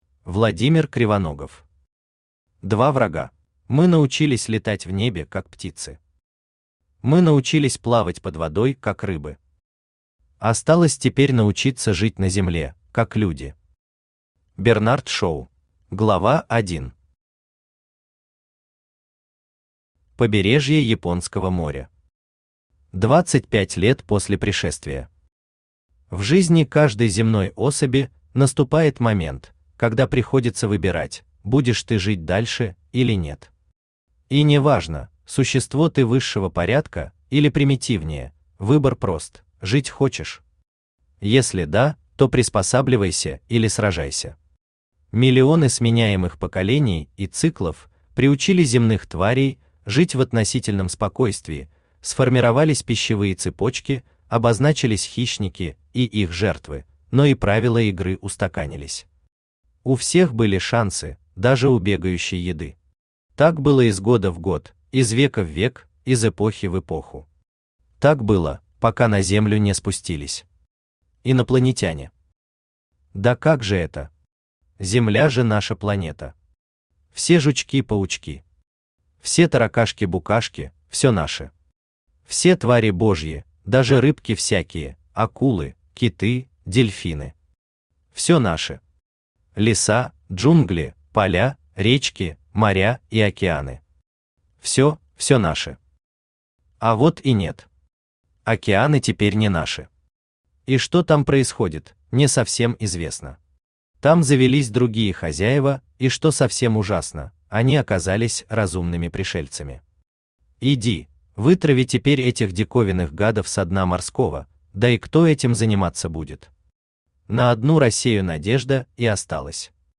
Аудиокнига Два врага | Библиотека аудиокниг
Aудиокнига Два врага Автор Владимир Кривоногов Читает аудиокнигу Авточтец ЛитРес.